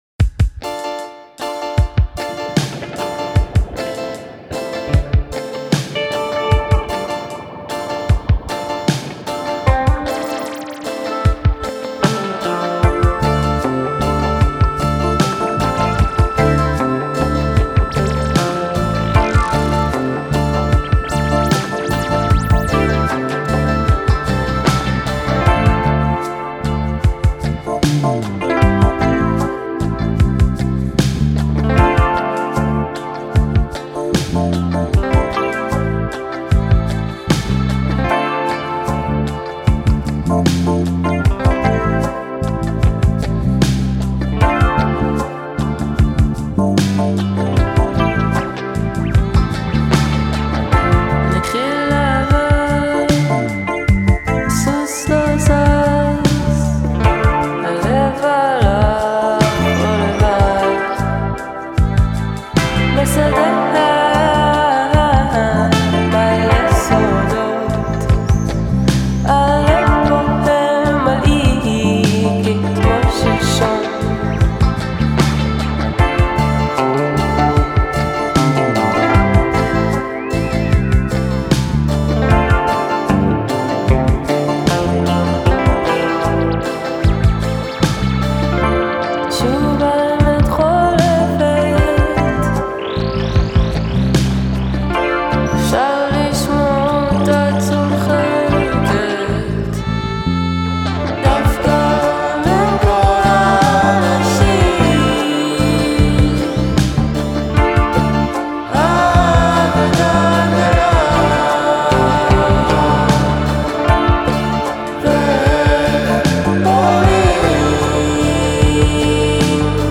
haunting vocals